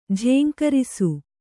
♪ jhēŋkarisu